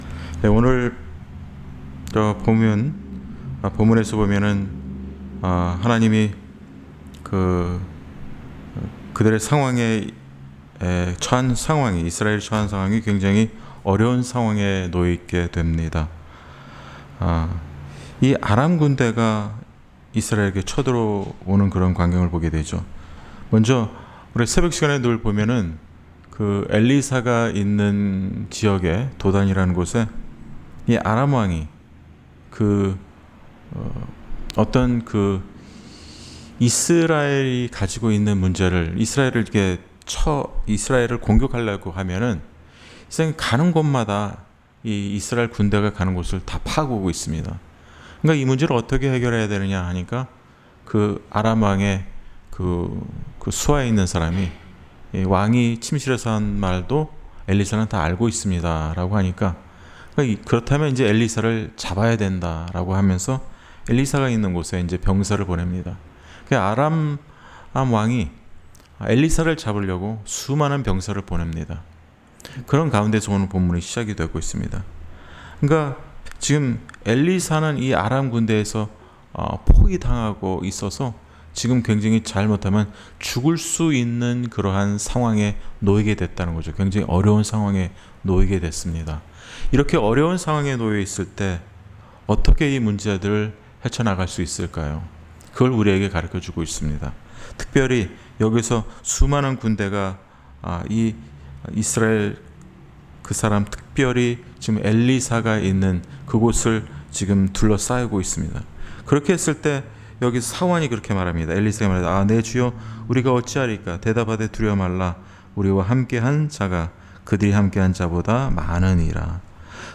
Series: 금요기도회